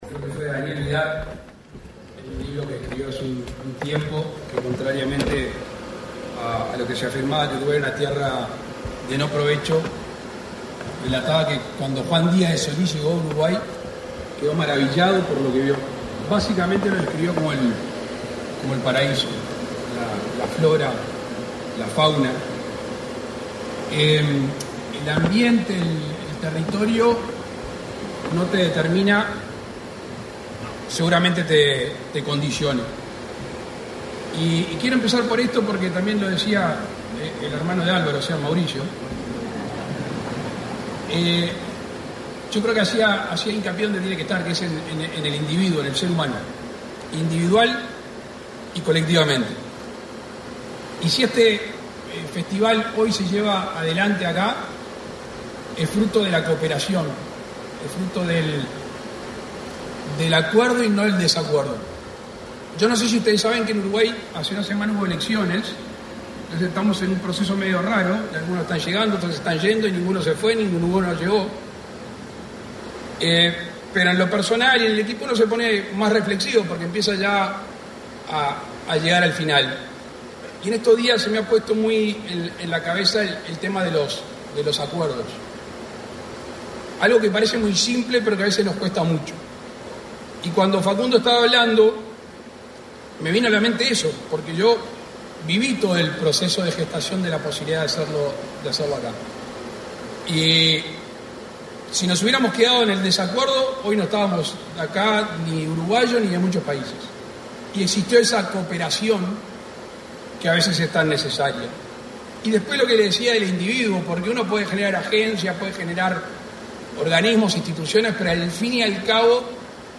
Palabras del presidente de la República, Luis Lacalle Pou
El presidente de la República, Luis Lacalle Pou, participó, este 2 de diciembre, en la ceremonia de apertura de Ventana Sur, el principal mercado
Presidente Lacalle Pou en apertura de Ventana Sur, en el teatro Solís